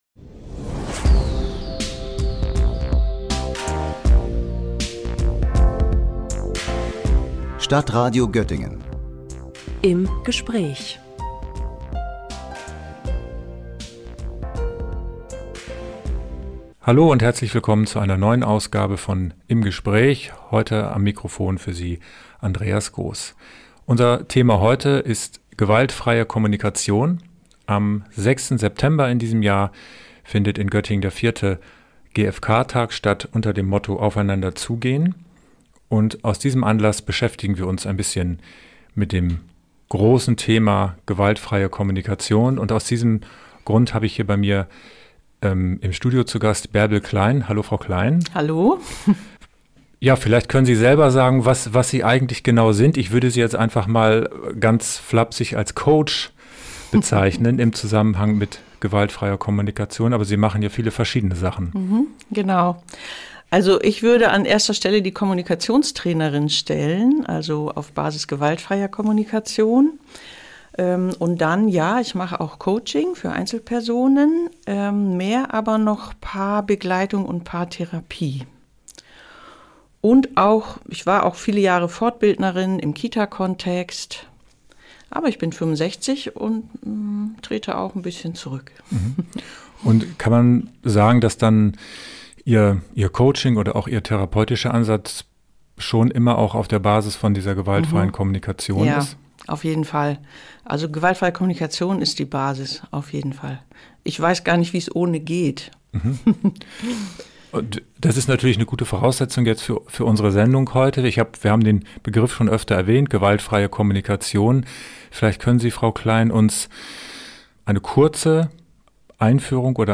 Wie meine Scham mir zur Freundin wird: Interview